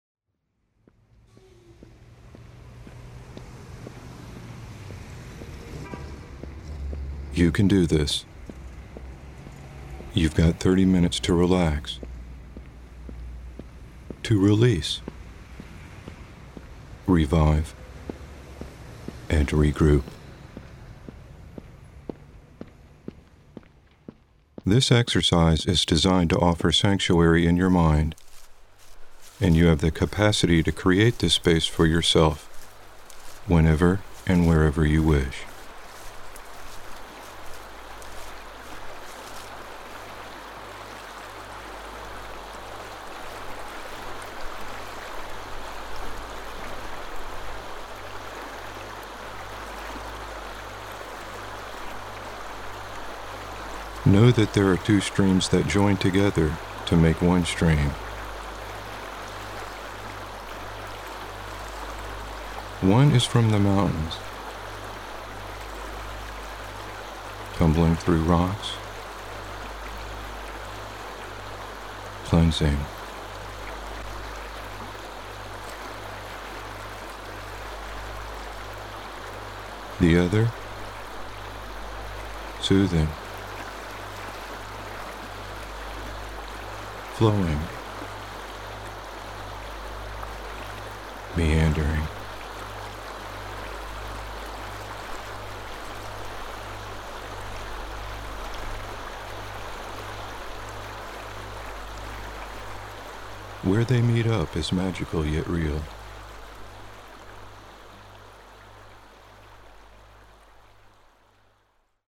Let your cares flow downstream as you relax and revive with this verbally-guided exercise.
This simple yet beautiful verbally-guided exercise offers a peaceful and refreshing respite from ongoing daily activities. Soothing live stream recordings combined with Hemi-Sync® frequencies create a sanctuary for a quiet retreat to use whenever you need to create some special time for yourself. Track 1 is verbally-guided; track 2 is a non-verbal 30-minute free flow for you to use anytime, or to extend your stay in the stream.